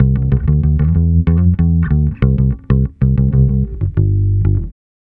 Track 13 - Bass 06.wav